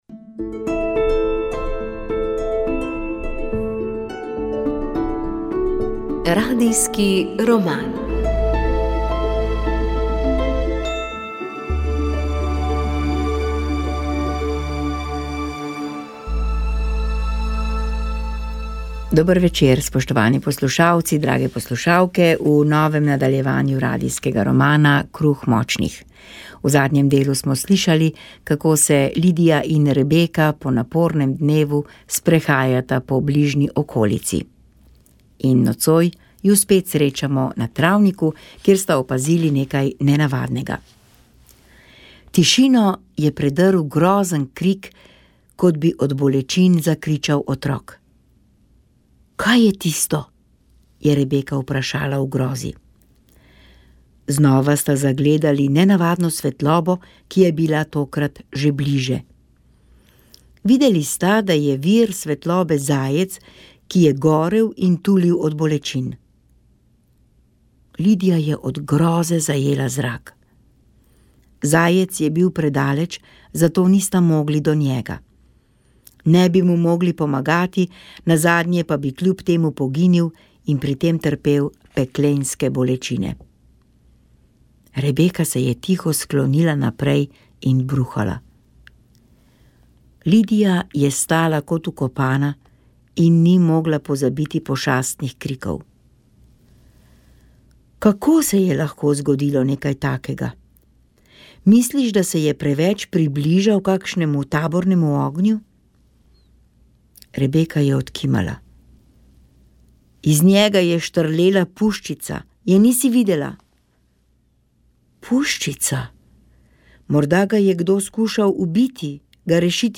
Informativne oddaje